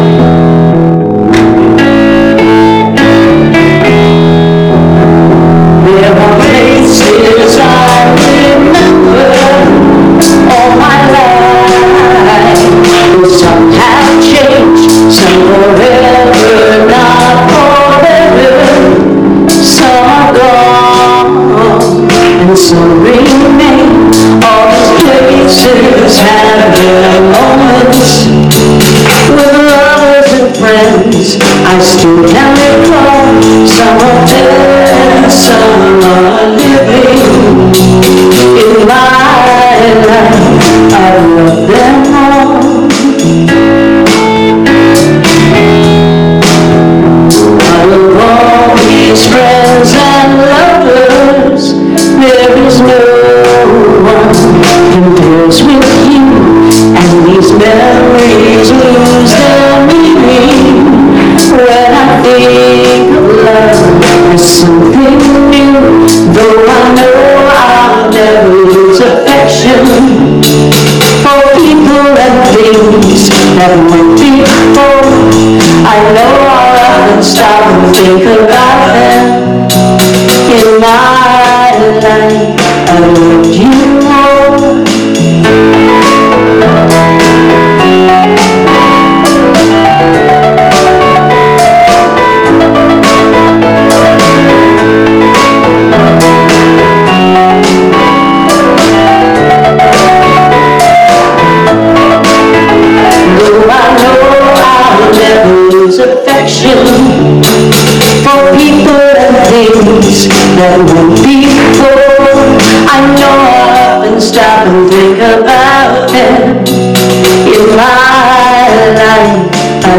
Spiritual Leader Series: Sermons 2023 Date